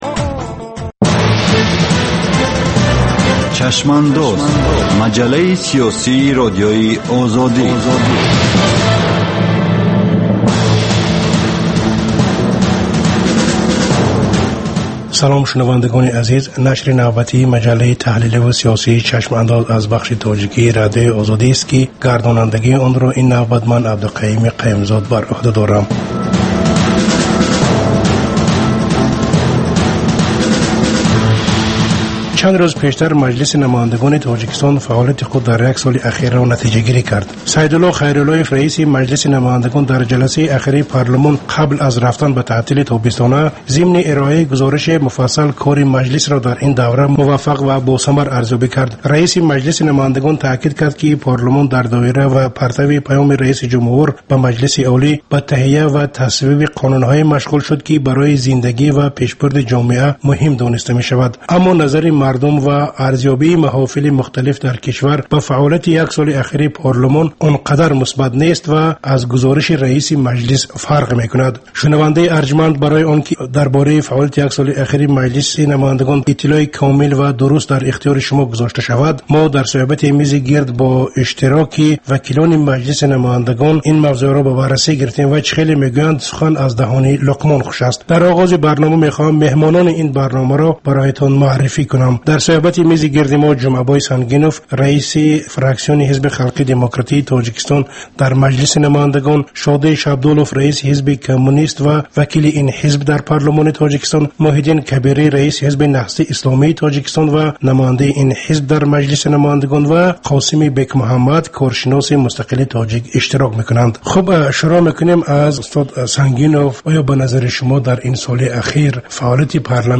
Баррасӣ ва таҳлили муҳимтарин рӯйдодҳои сиёсии рӯз дар маҷаллаи "Чашмандоз". Гуфтугӯ бо коршиносон, масъулини давлатӣ, намояндагони созмонҳои байналмилалӣ.